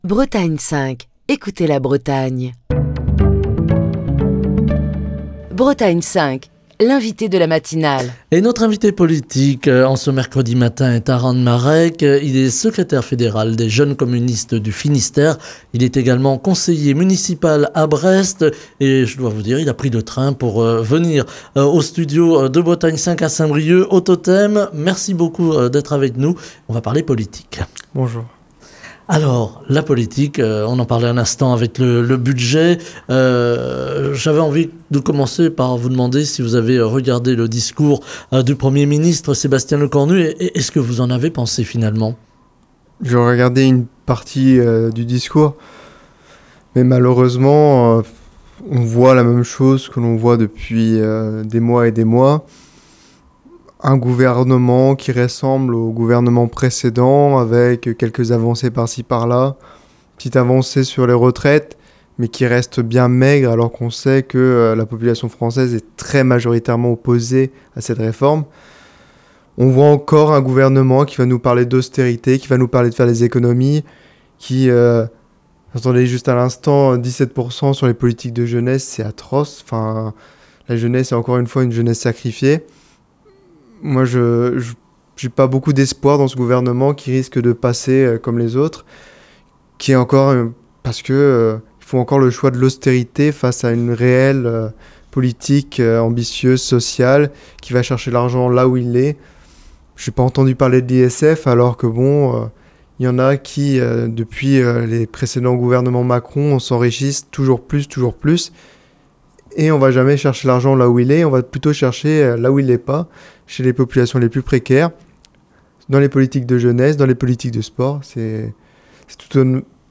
Taran Marec, secrétaire fédéral des Jeunes communistes du Finistère, conseiller municipal à Brest, était l'invité politique